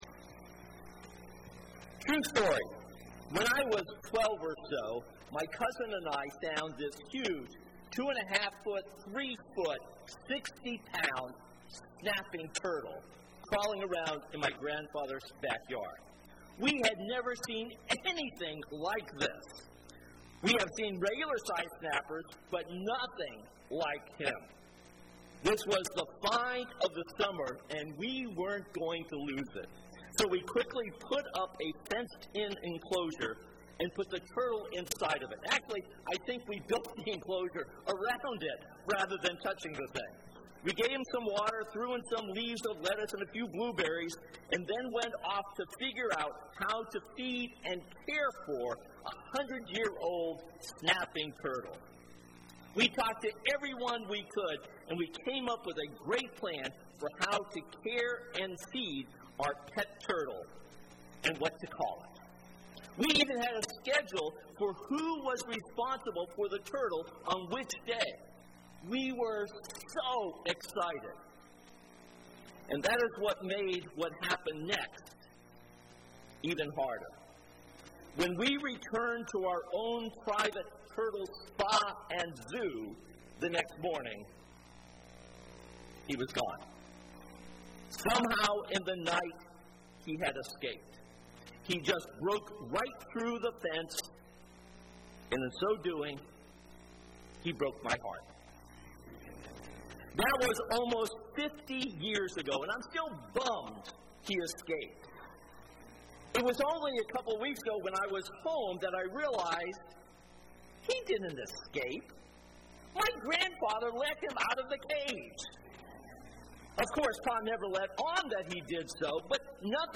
This sermon is based on 1 Peter 5:1-4.